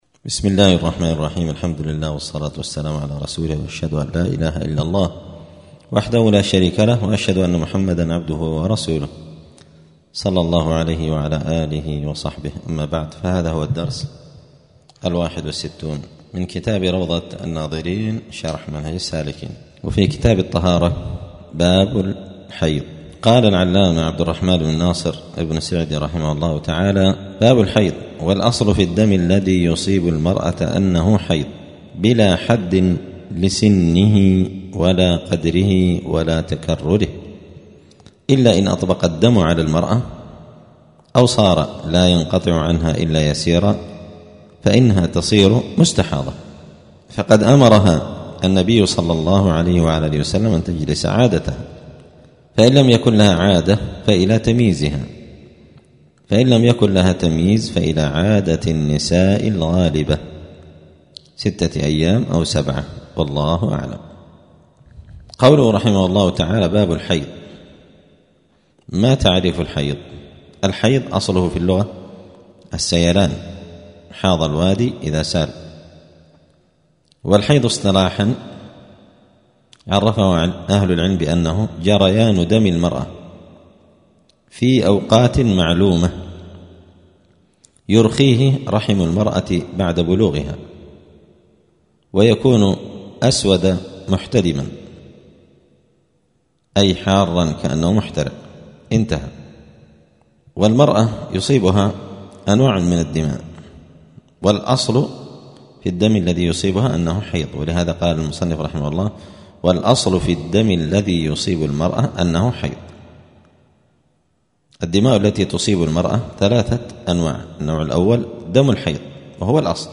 *الدرس الحادي والستون (61) {كتاب الطهارة باب الحيض تعريف الحيض}*